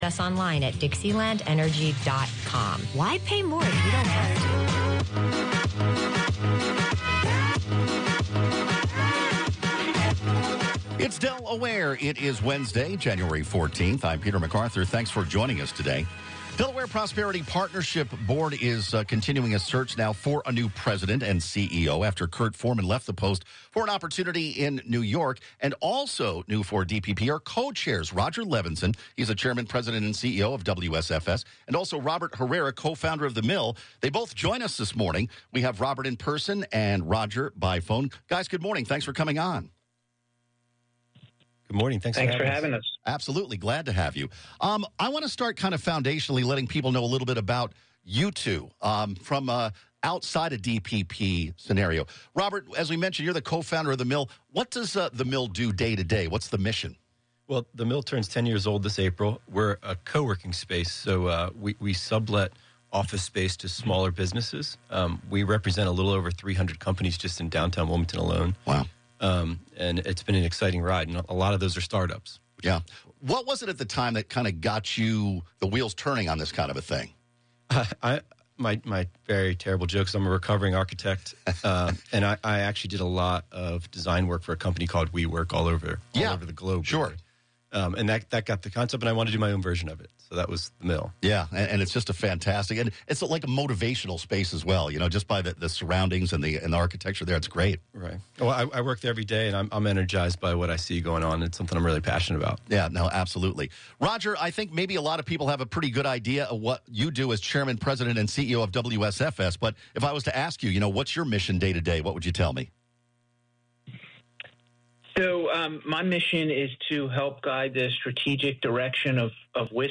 Delaware Prosperity Partnership and WDEL 1150 AM/101.7 FM Radio pairs on-air interviews of key players in Delaware’s economic development.